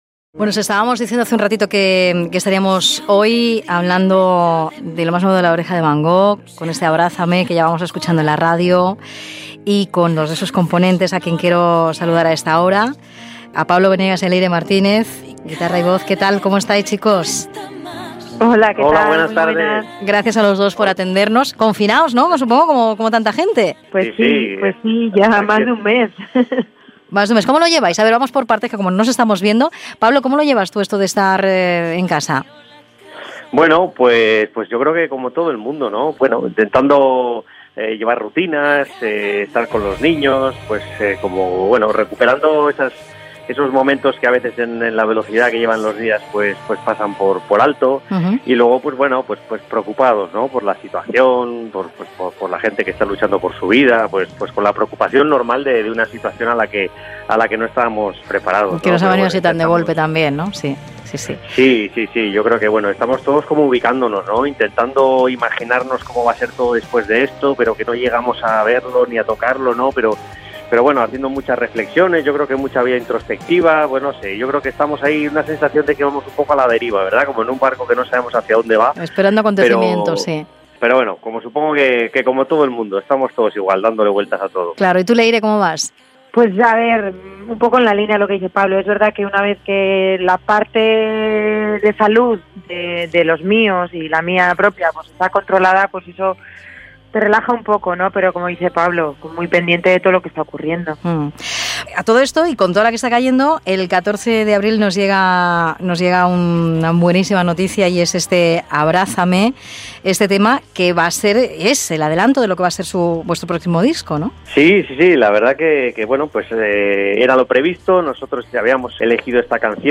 Entrevista telefònica a dos integrants del grup La oreja de Van Gogh en el període de confinament degut a la Covid 19.
Entreteniment